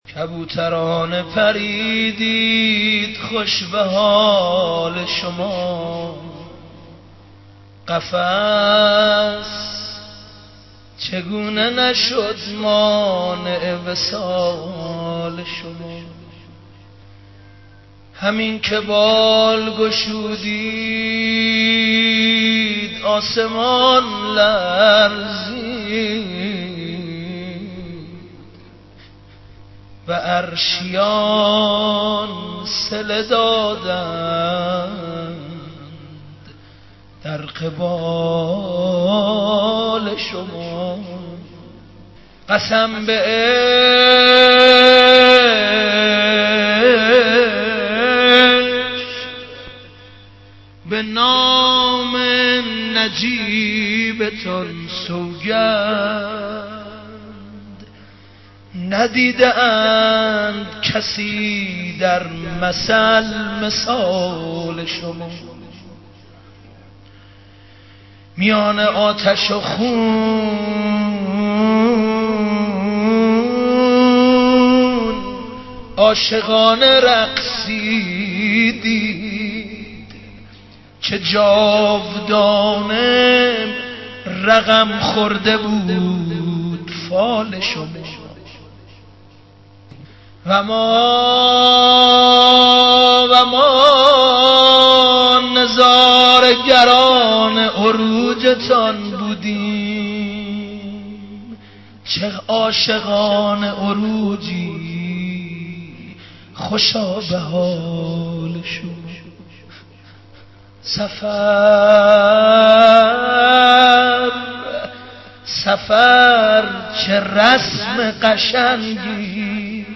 maddahi-214.mp3